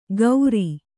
♪ gauri